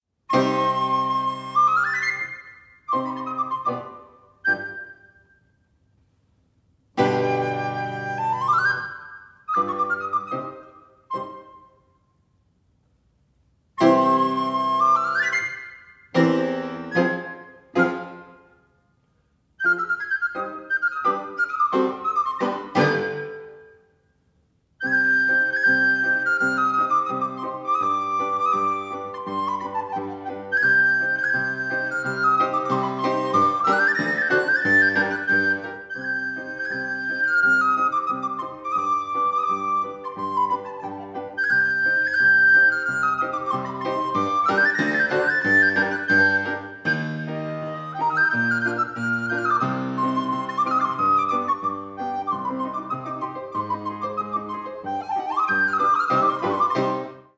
recorders
harpsichord, organ and fortepiano
cello